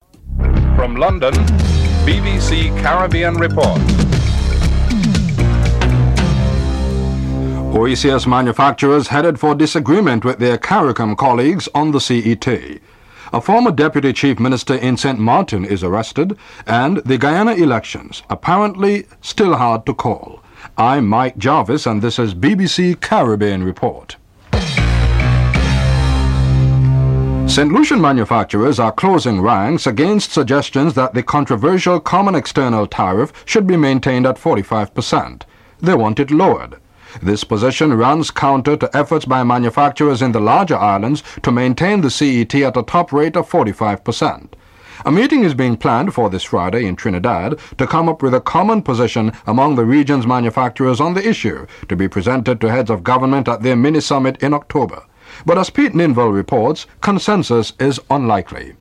1. Headlines (00:00-00:26)